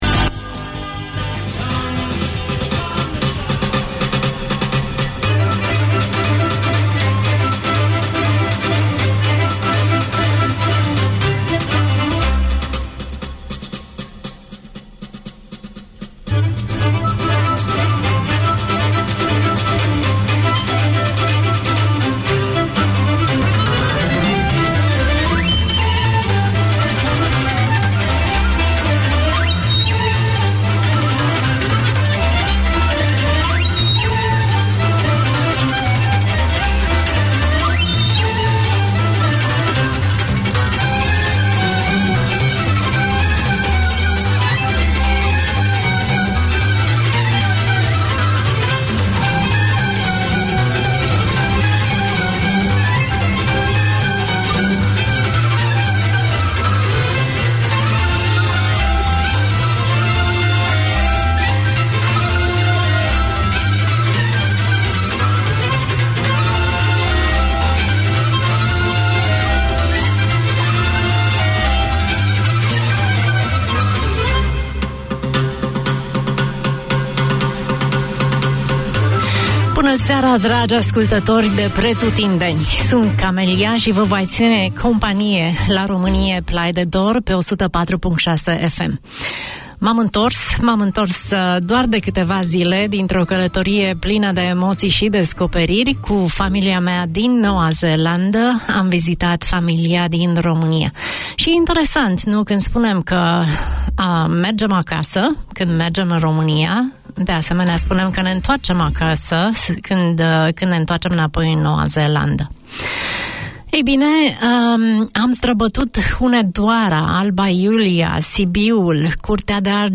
This 60 minute show presents news from Romania and from the Romanian community living in Auckland. The show introduces local and touring personalities, arts and sports news and children’s segments, shares humour and favourite Romanian music and takes live calls from listeners.